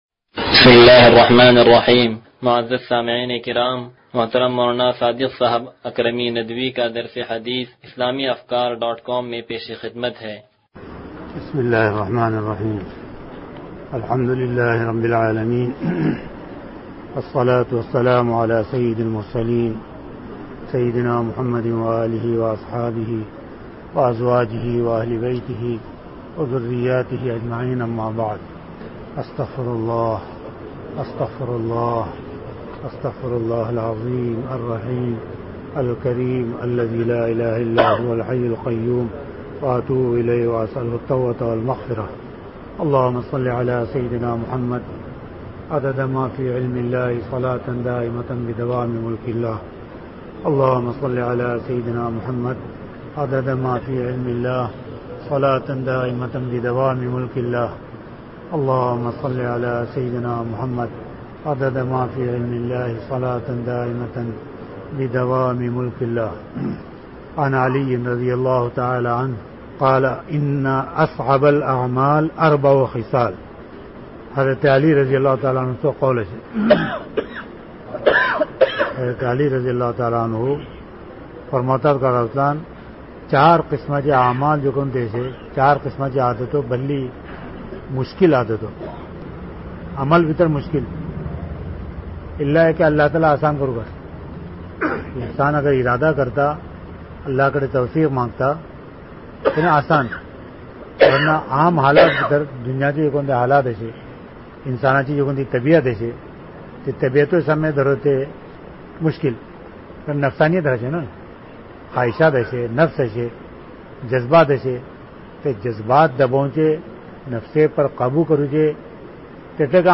درس حدیث نمبر 0093